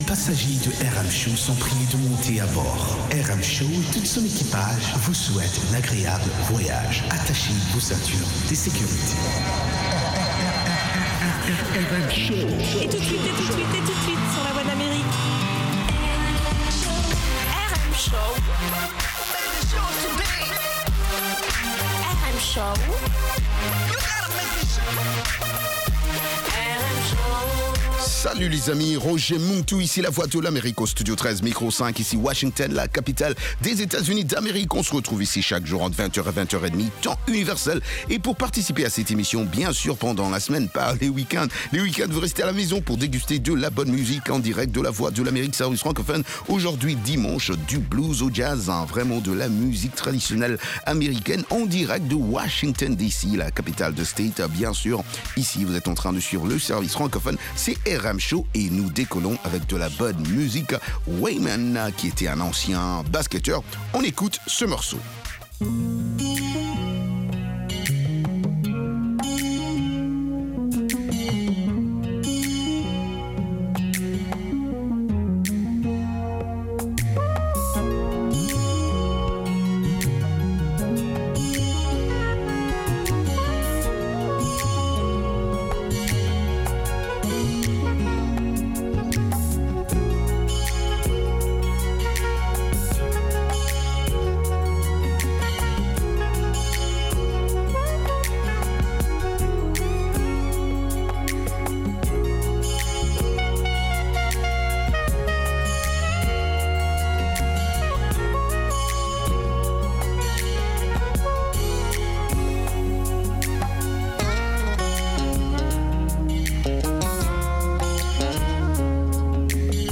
RM Show - French du blues au jazz